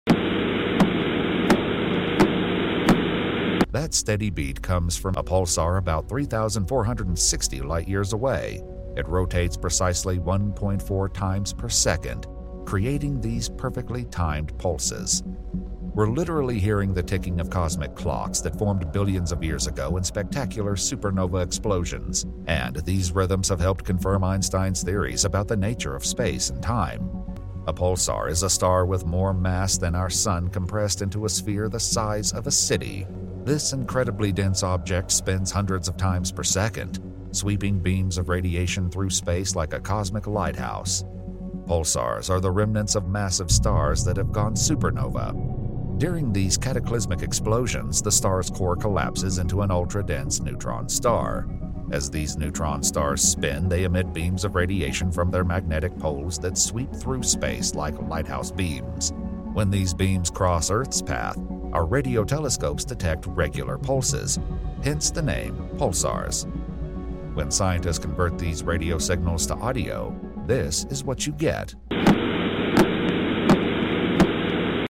The sound of a Pulsar sound effects free download
The sound of a Pulsar star.